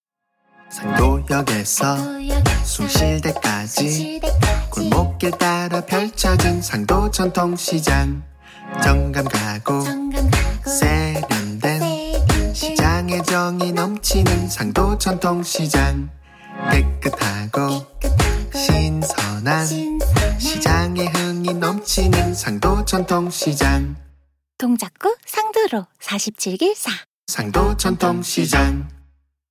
로고송 다운로드-블로그